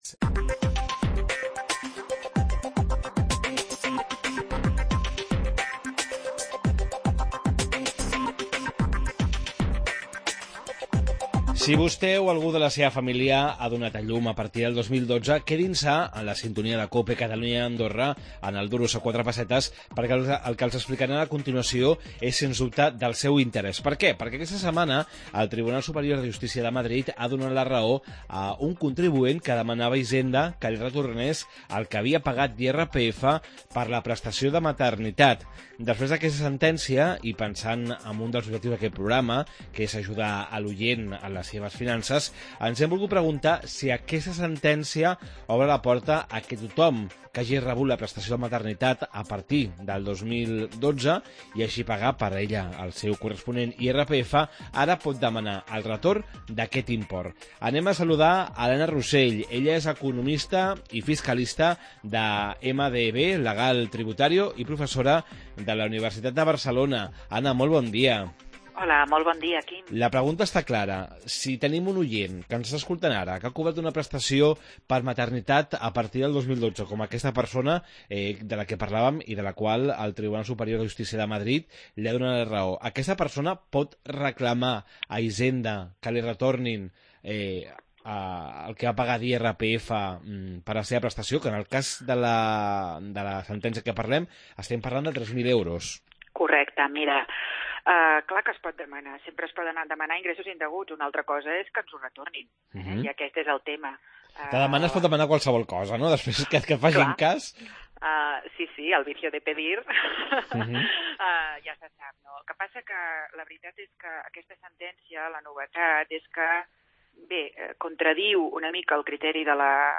economista i fiscalista